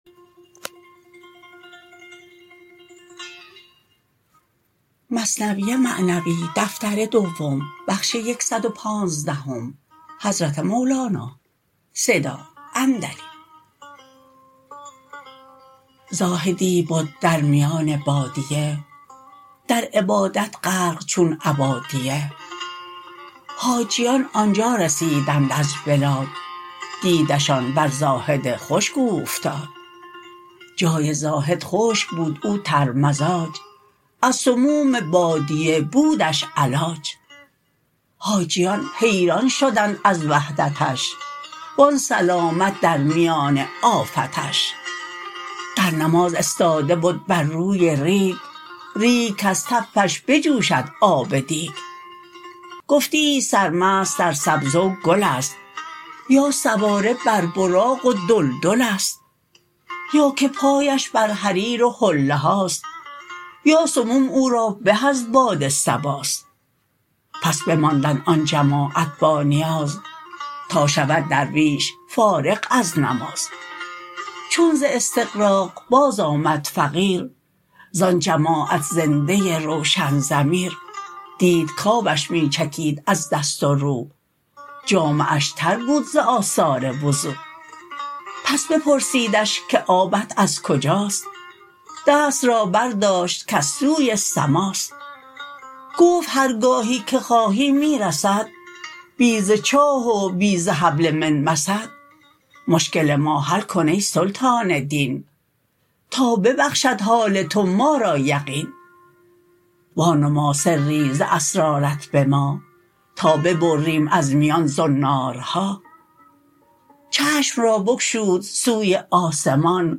بخش ۱۱۵ - حیران شدن حاجیان در کرامات آن زاهد کی در بادیه تنهاش یافتند از (مولانا » مثنوی معنوی » دفتر دوم) را با خوانش
متن خوانش: